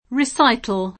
riS#itëN] s. m. (in it.); inv. (pl. ingl. recitals [